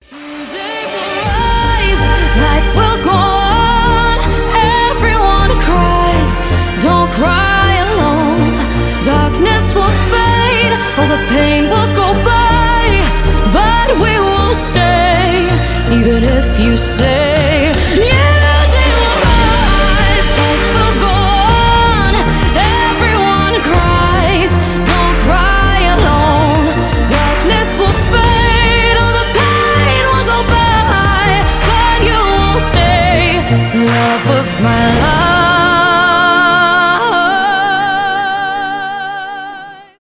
красивый вокал , вдохновляющие
сильный голос
поп